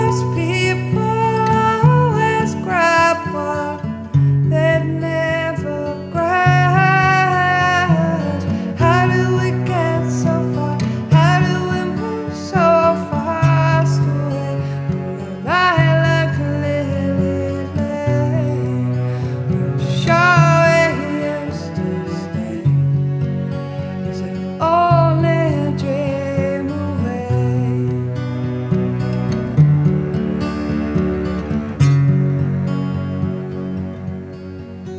Жанр: Электроника / Рок / Альтернатива / Фолк-рок